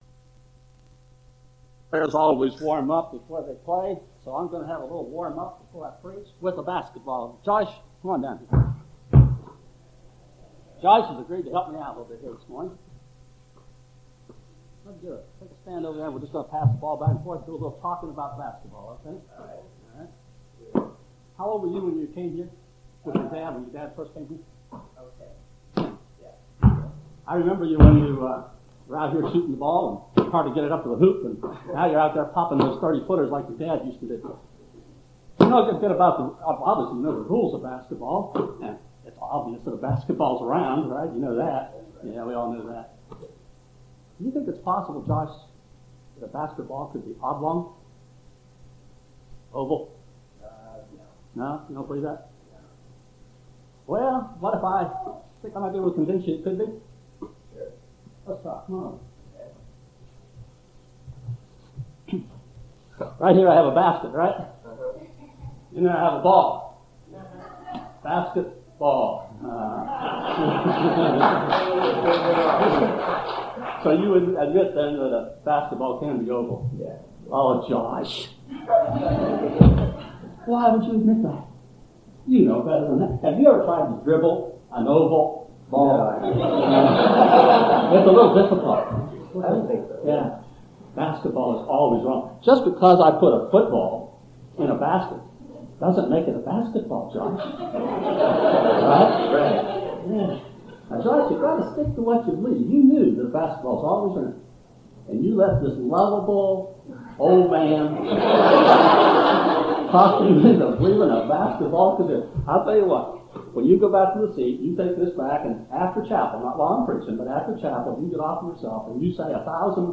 Distinguished Faculty Sermons - St. Louis Christian College Alumni
A Sermon for the Graduates